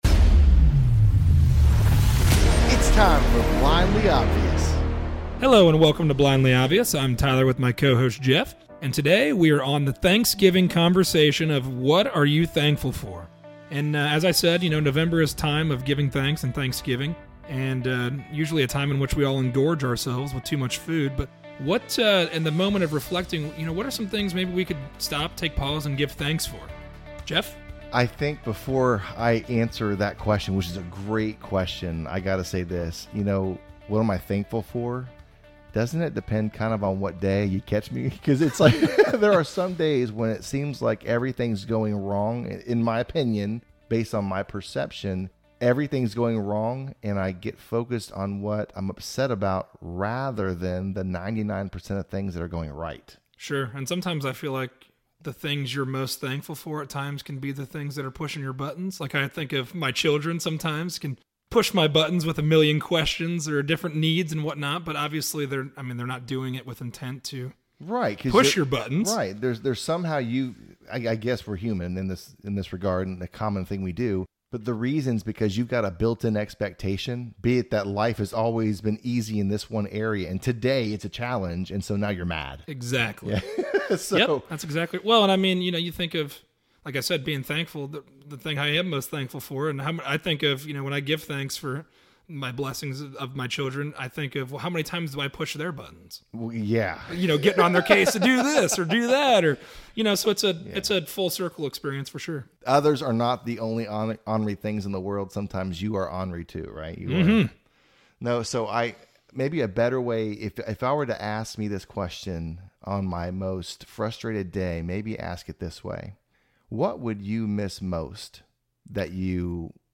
A conversation on giving thanks and what it is we are thankful for. Is giving thanks the consistency we need to see all of the good in our lives?